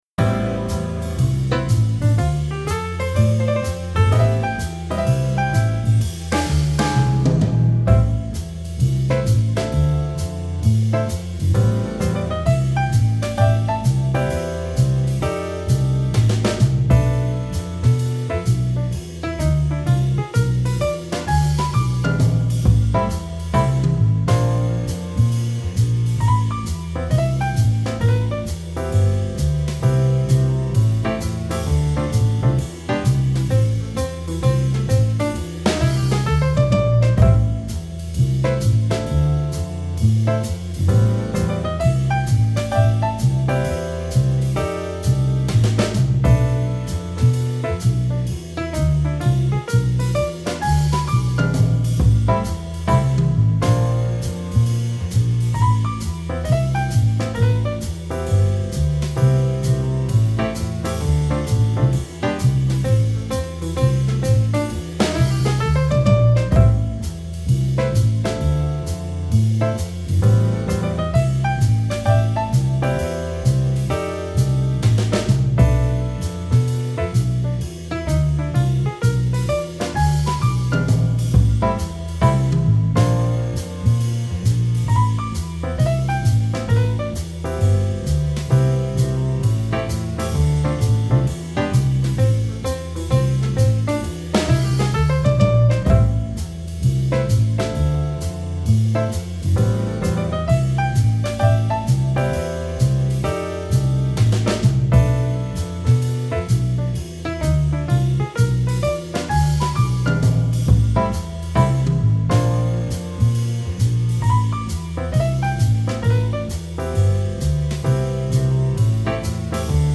Vocal Swing